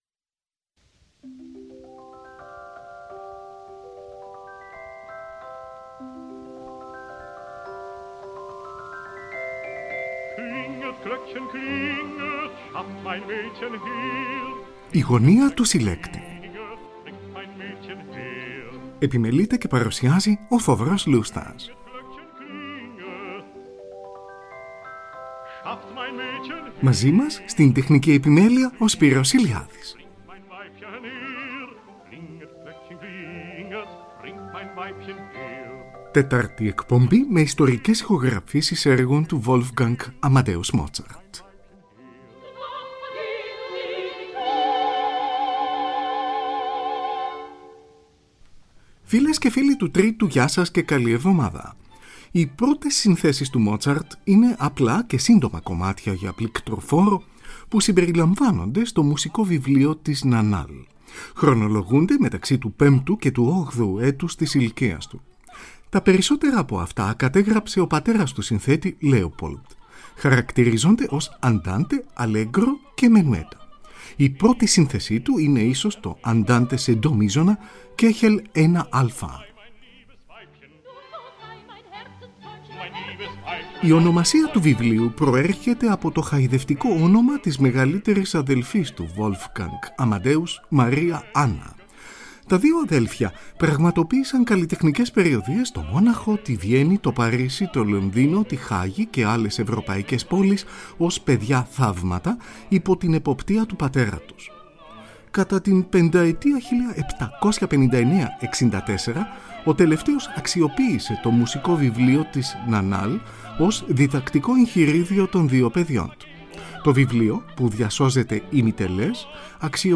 ΙΣΤΟΡΙΚΕΣ ΗΧΟΓΡΑΦΗΣΕΙΣ ΕΡΓΩΝ ΤΟΥ WOLFGANG AMADEUS MOZART (TETAΡΤΟ ΜΕΡΟΣ)
Μενουέτο για πληκτροφόρο, Κ. 2.
Μενουέτο για πληκτροφόρο, Κ. 4.